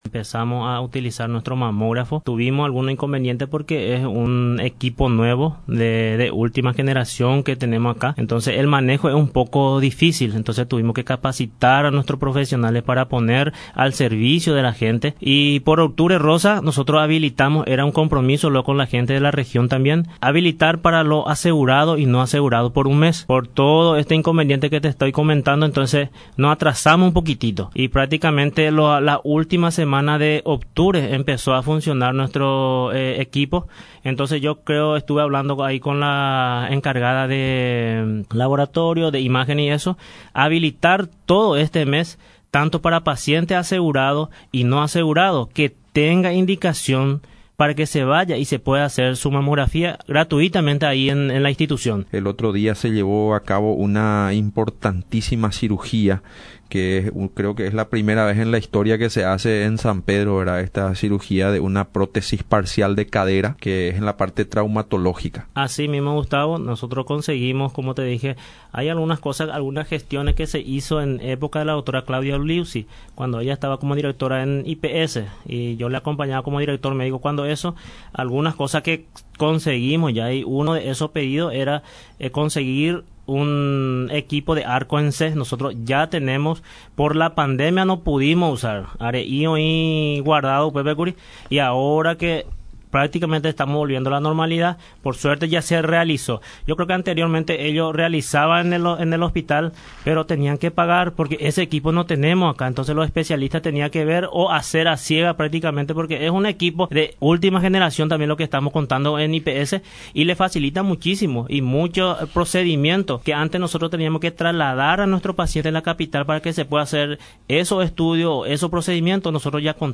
en visita a los estudios de Radio Nacional San Pedro 105.9 FM.